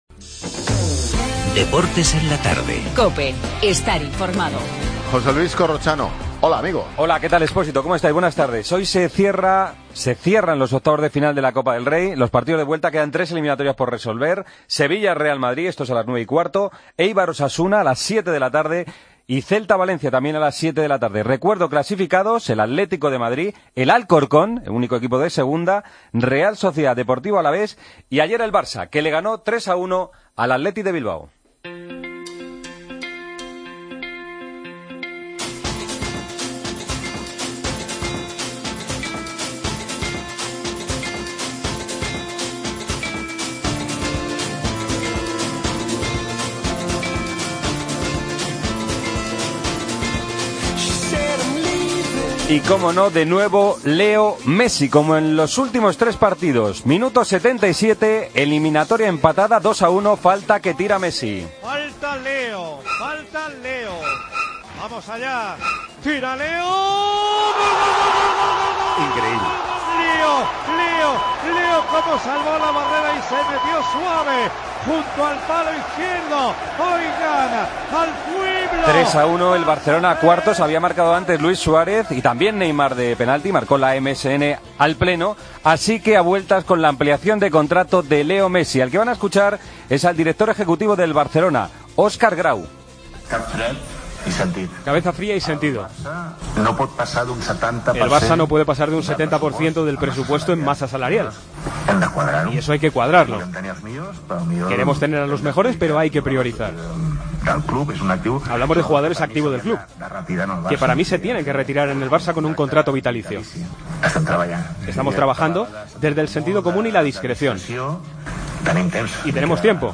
Titulares del día.